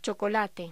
Locución: Chocolate
voz